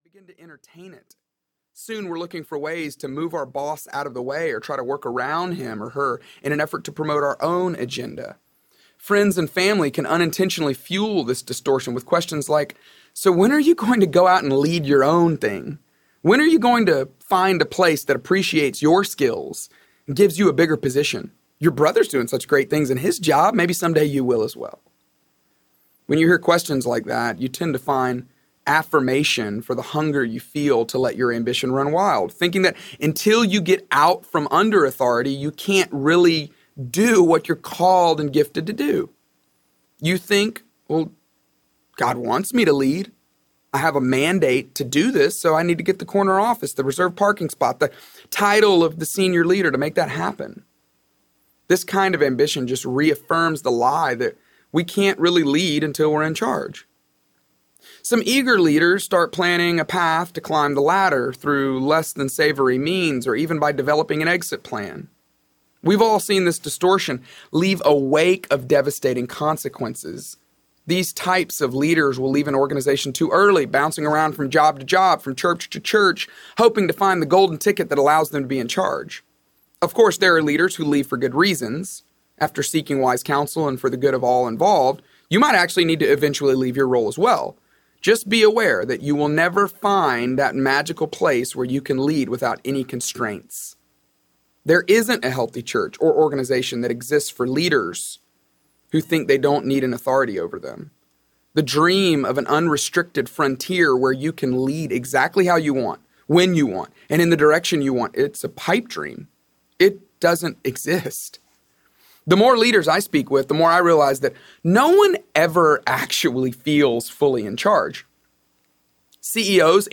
How to Lead When You’re Not in Charge: Leveraging Influence When You Lack Authority Audiobook